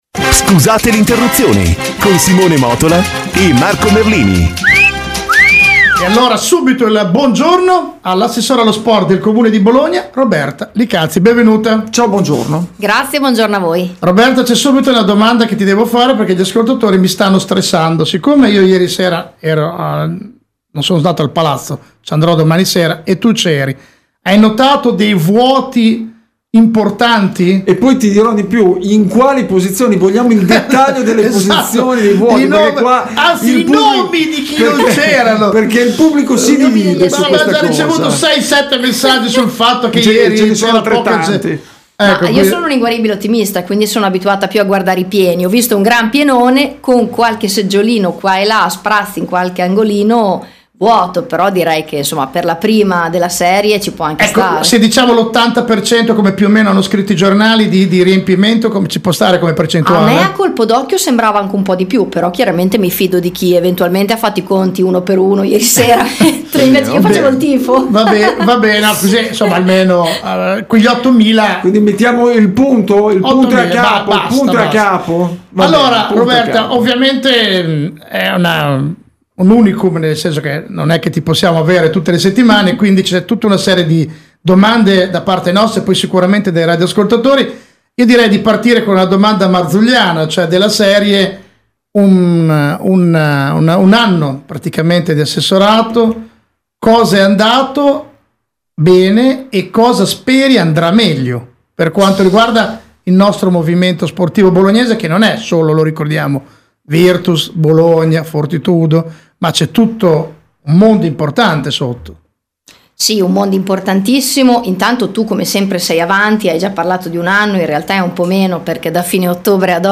Intervista a Roberta Li Calzi - Assessora allo Sport e al Bilancio del Comune di Bologna - Radio International